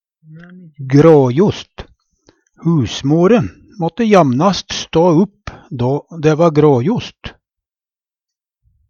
gråjost - Numedalsmål (en-US)
Fleirtal gråjos gråjost gråjose gråjose gråjose Eksempel på bruk Husmore måtte jamnast stå upp når dæ va gråjost Hør på dette ordet Ordklasse: Adjektiv Attende til søk